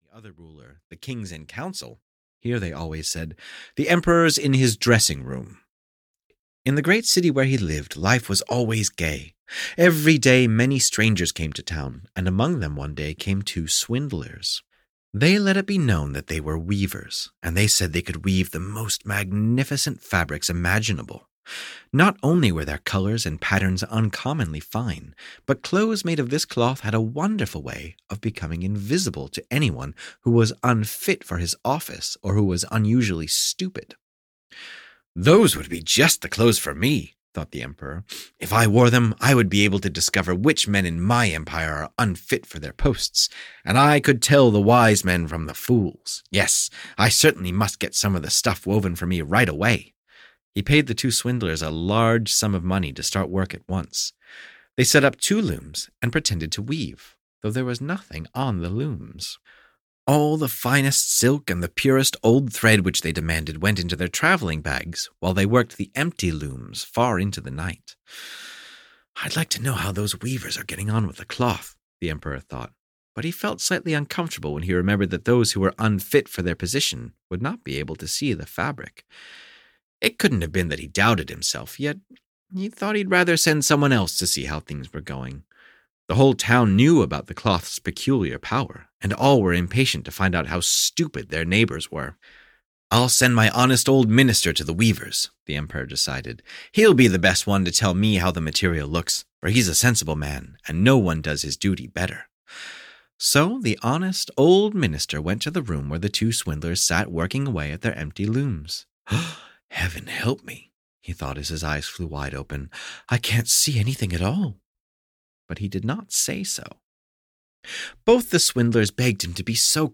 Tales About Truth (EN) audiokniha
Ukázka z knihy